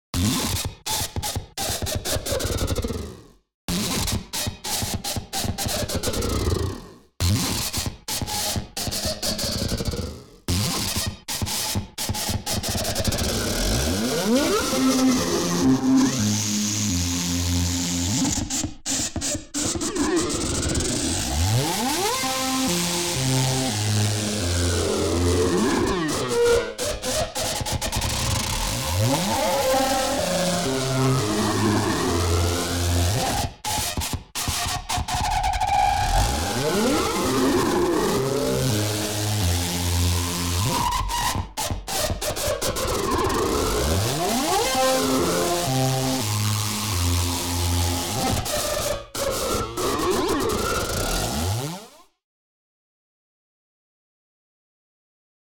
Keeping experimenting with feedback loops. Mixing physmod with exponential rhythms is really fun.